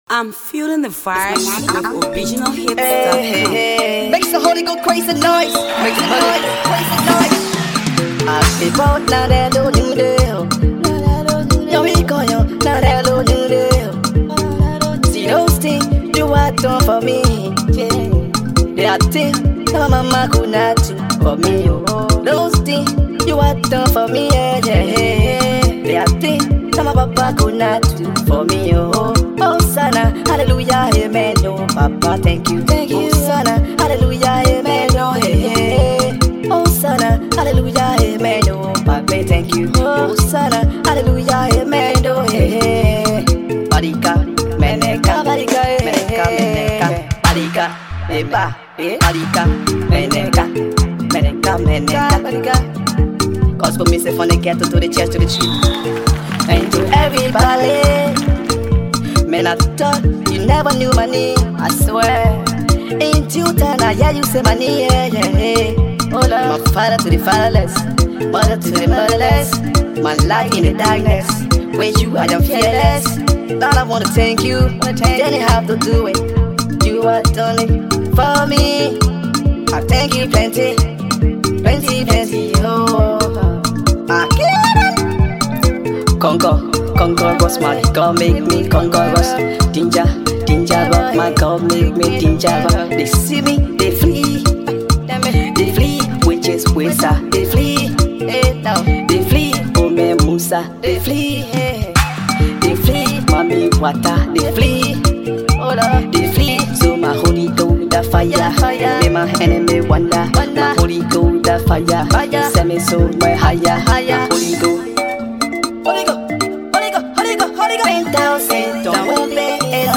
praise tune